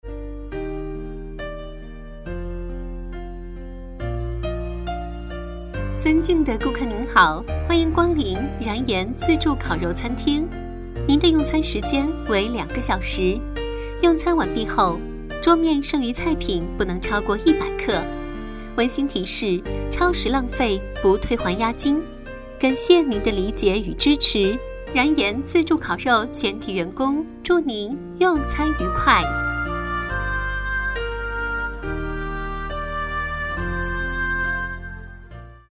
定制彩铃-女8--餐厅温馨提示.mp3